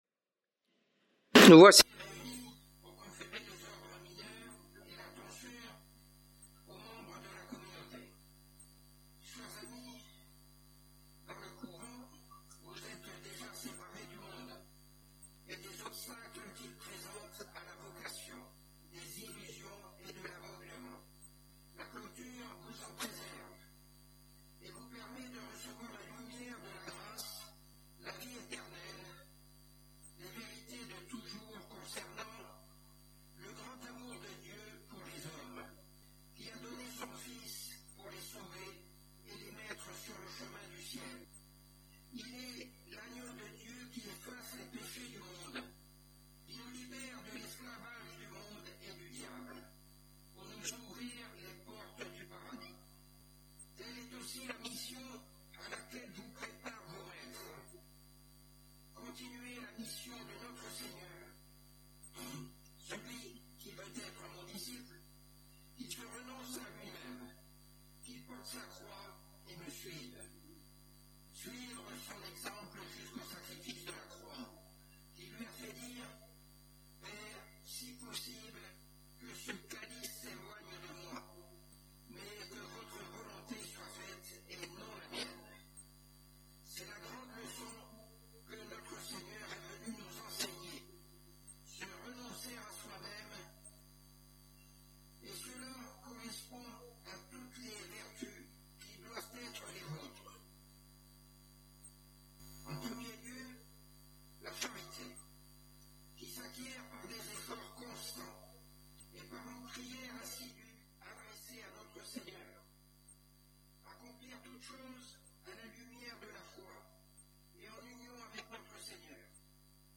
Occasion: Cérémonie : Tonsures et ordres mineurs
Type: Sermons